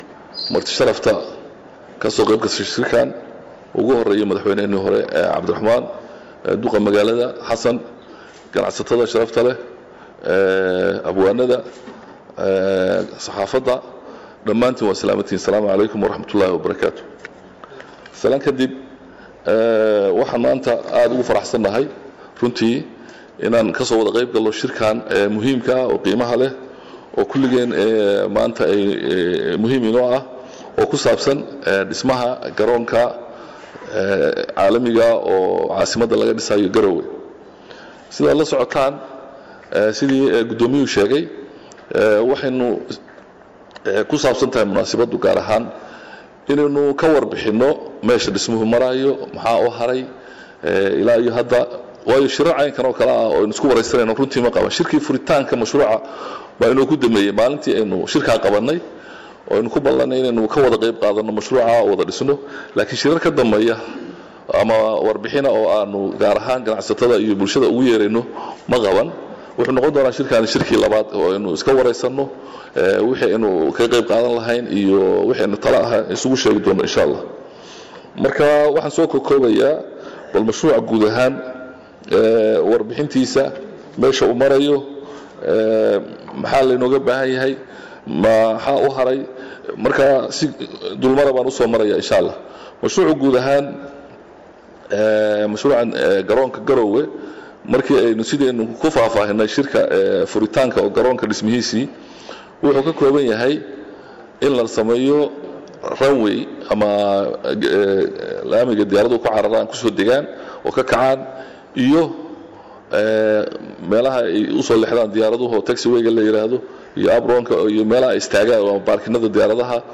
19 nov 2016 (Puntlandes) Waxaa ka dhacay Caasimadda Puntland shir looga hadlayey sidii looga qayb qaadan lahaa Nal gelinta Garoonka Diyaarahada Garowe oo dhismihiisu marayo heer gabogabo ah.
Shirka ayaa dadkii waxaa ka mid ahaa WasiirkaWasaaradda Duulista Hawada iyo Garoomada Puntland Xasan Xaaji Siciid oo sharaxaad dheer ka bixiyey meesha uu marayo dhismaha Garoonka Diyaaradaha Garoonka Garowe.